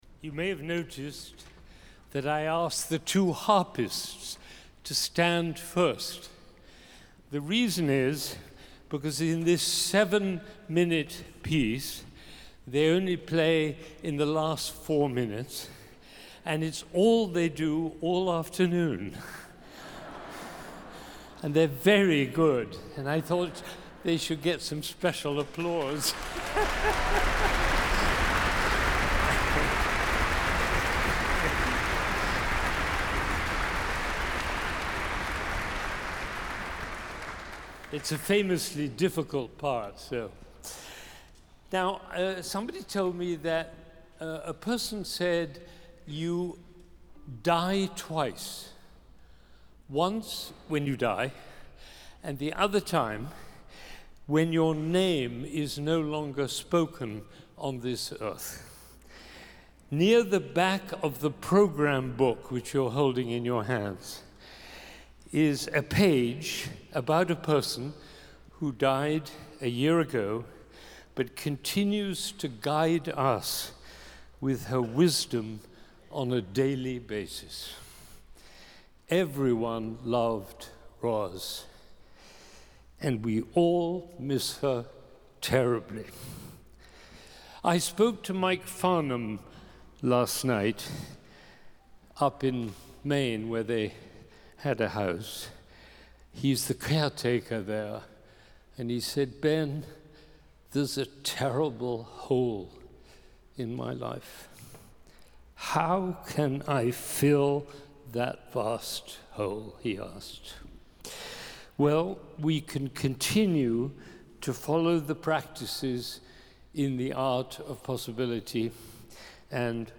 Recorded Live at Symphony Hall on November 3rd, 2024. Boston Philharmonic Youth Orchestra Benjamin Zander, conductor